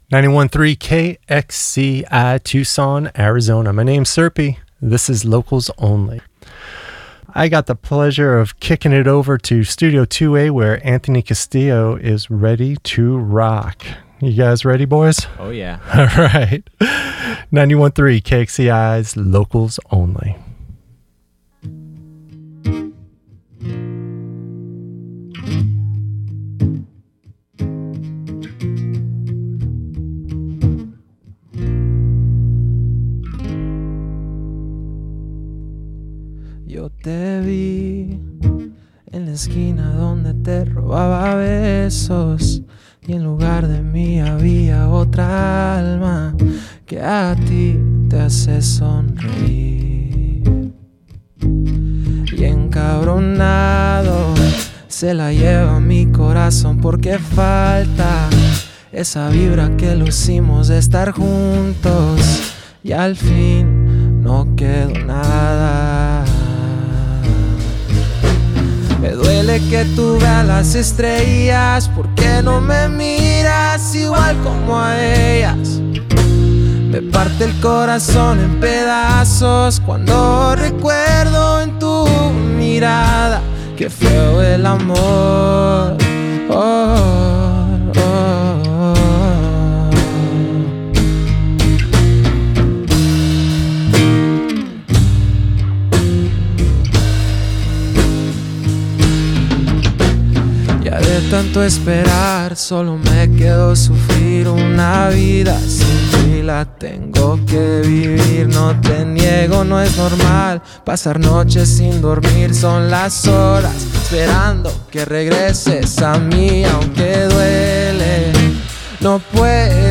Listen to the live performance + interview here!
live in Studio 2A
melancholic, melody-driven Regional Mexicano sound
live interview
Singer/Songwriter